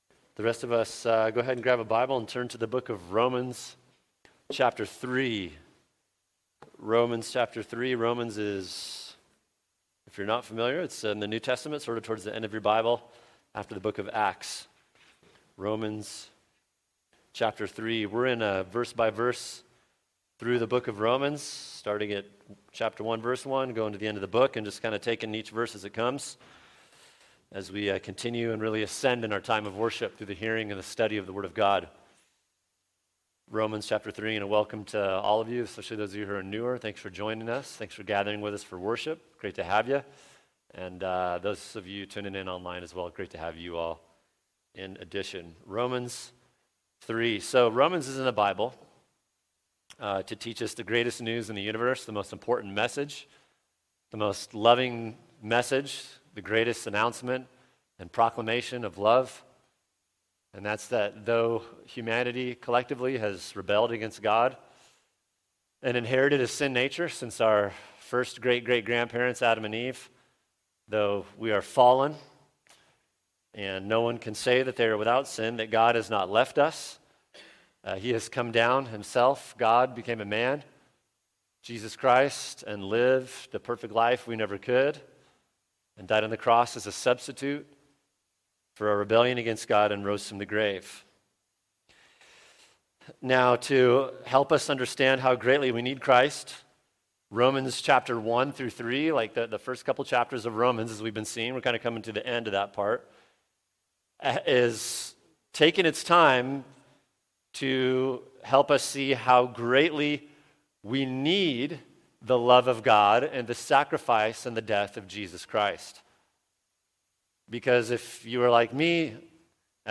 [sermon] Romans 3:12-18 Total Depravity | Cornerstone Church - Jackson Hole